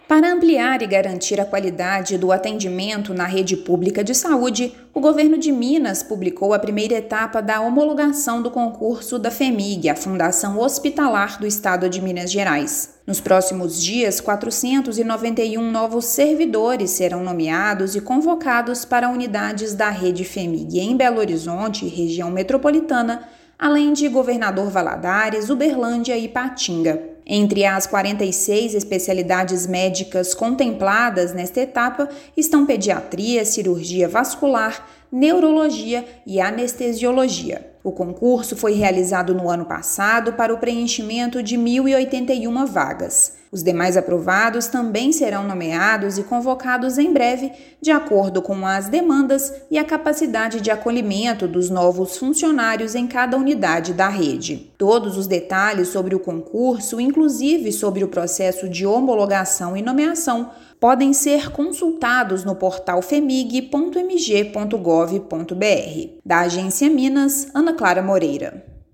Quase 500 profissionais de 46 especialidades vão reforçar a assistência na Rede Fhemig; todos os demais candidatos aprovados dentro do número de vagas serão contemplados nas próximas etapas. Ouça matéria de rádio.